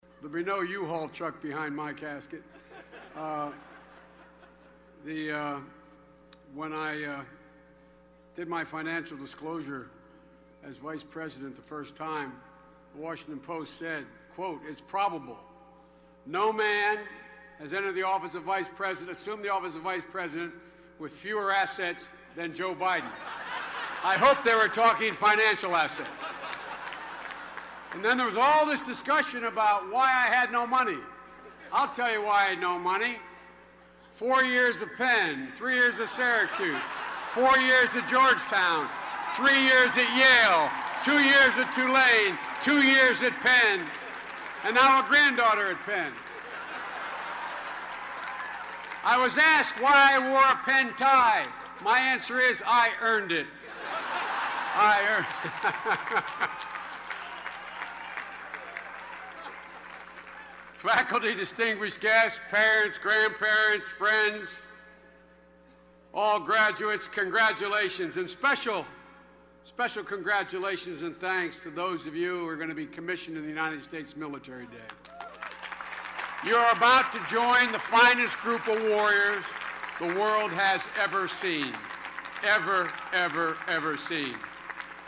公众人物毕业演讲第404期:拜登2013宾夕法尼亚大学(2) 听力文件下载—在线英语听力室